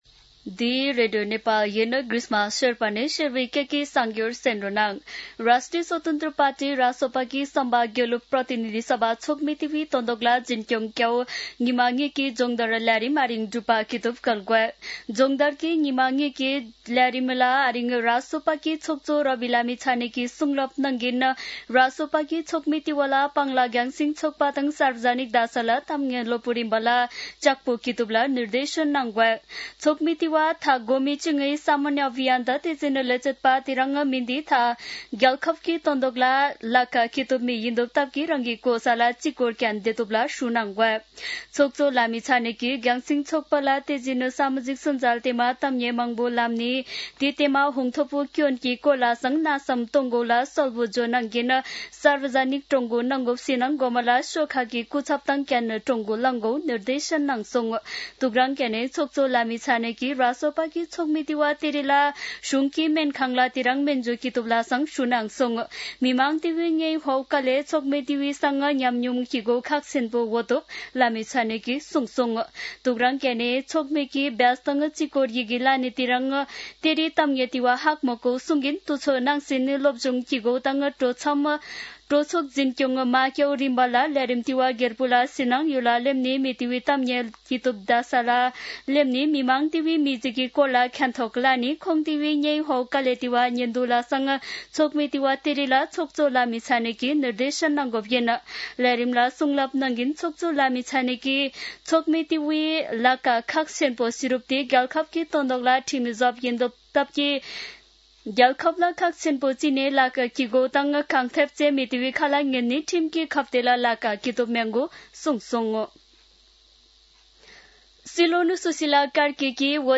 शेर्पा भाषाको समाचार : ४ चैत , २०८२
Sherpa-News-04.mp3